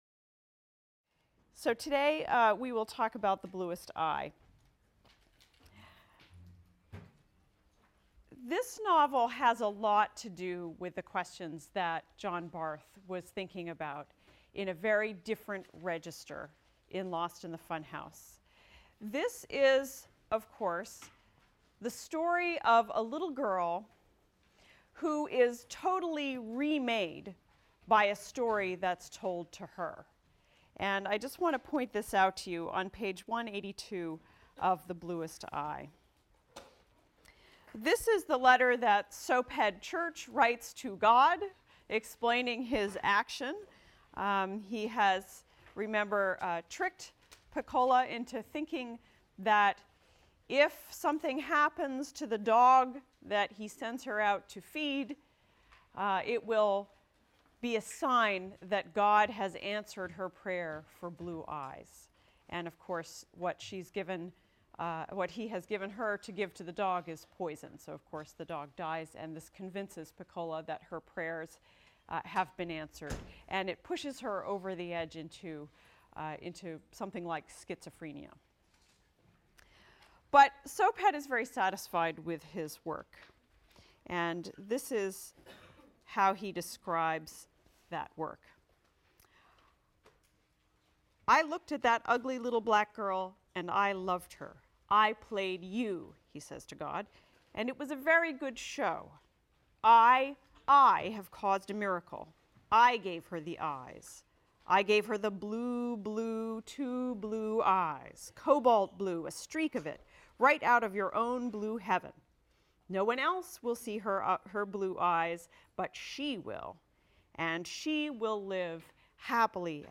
ENGL 291 - Lecture 13 - Toni Morrison, The Bluest Eye | Open Yale Courses